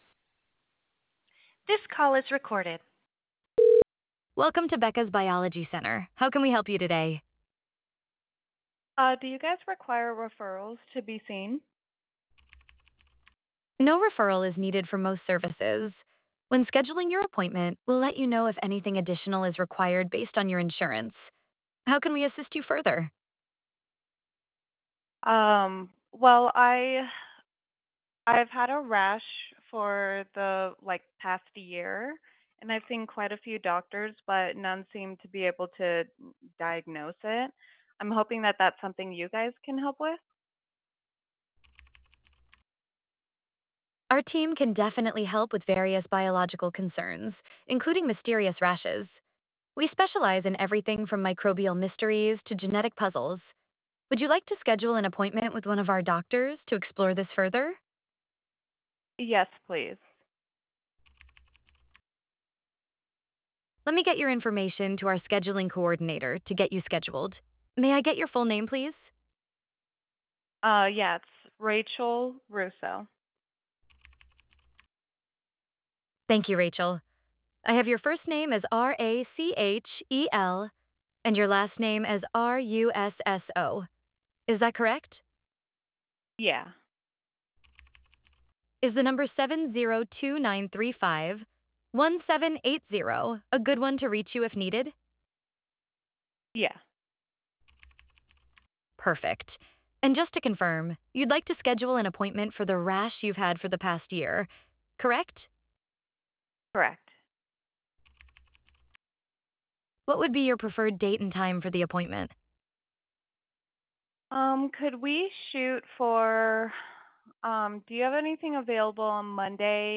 Click below to listen to real virtual receptionist call samples—professional, friendly, and always on brand.
AI-Healthcare.wav